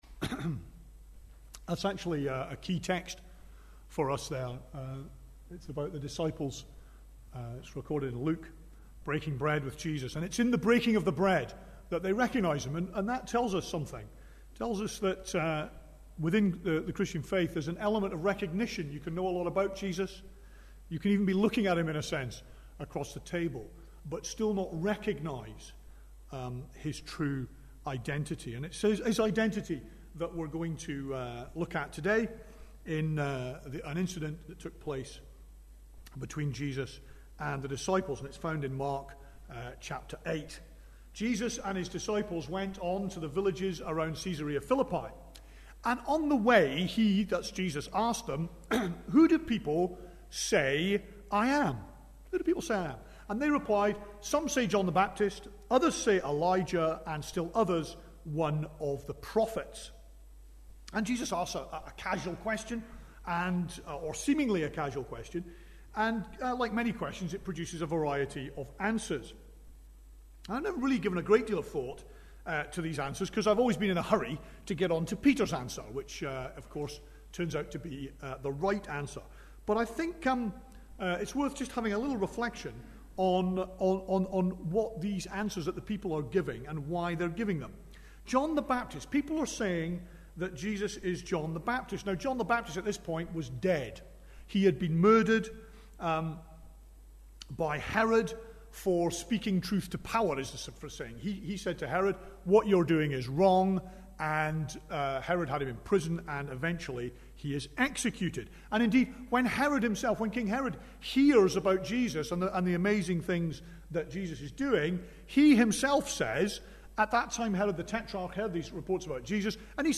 Mark 8:27-38 Service Type: Sunday Morning Bible Text